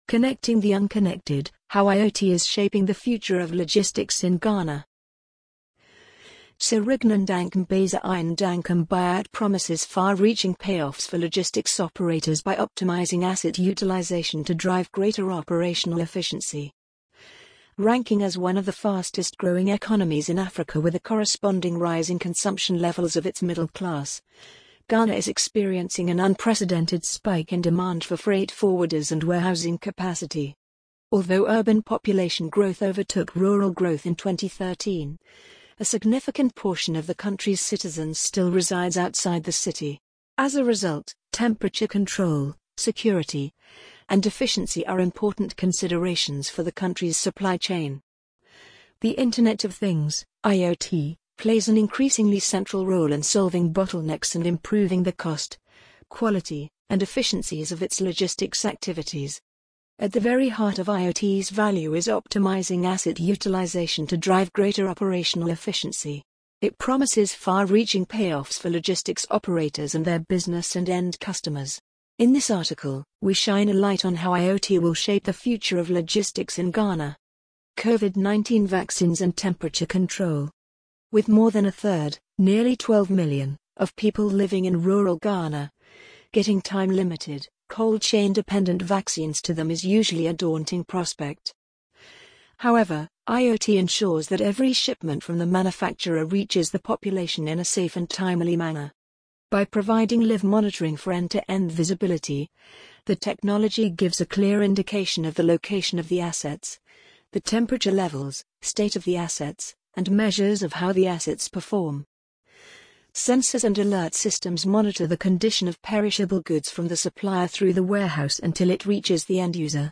amazon_polly_27864.mp3